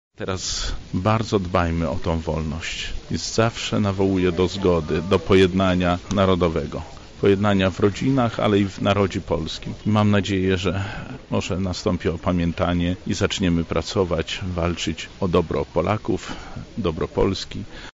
Miasto upamiętniło dziś pod pomnikiem Czechowicza zbombardowanie Lublina.